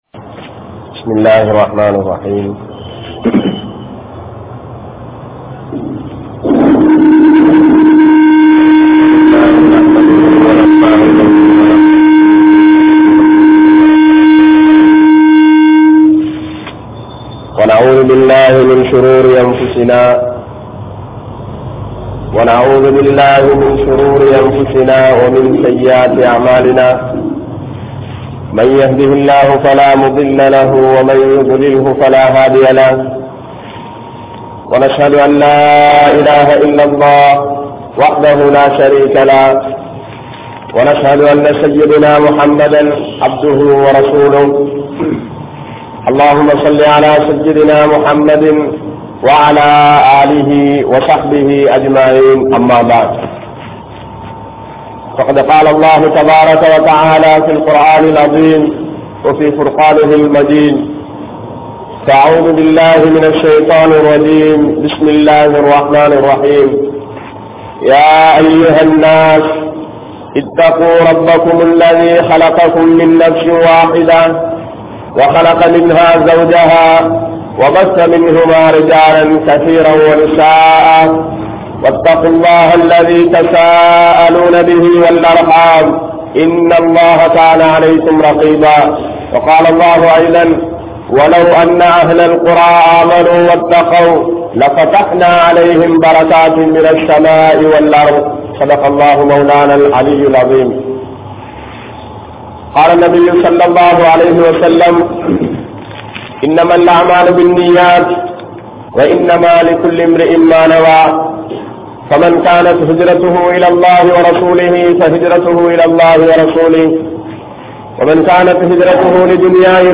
Dhunyaavukkaaha Eemaanai Vidaatheerhal (துன்யாவுக்காக ஈமானை விடாதீர்கள்) | Audio Bayans | All Ceylon Muslim Youth Community | Addalaichenai
Mathurankadawela Jumua Masjidh